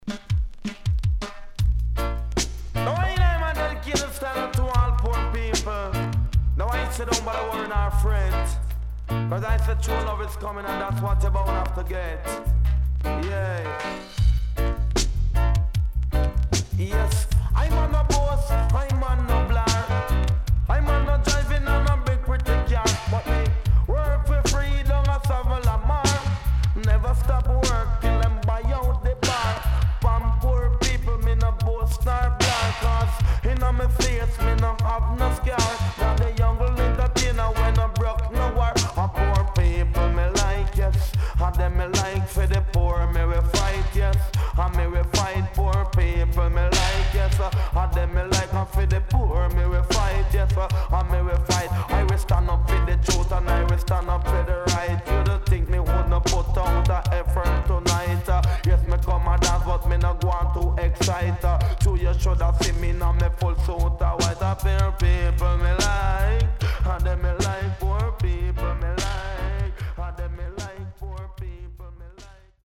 HOME > DISCO45 [DANCEHALL]  >  KILLER
SIDE B:所々チリノイズがあり、少しプチノイズ入ります。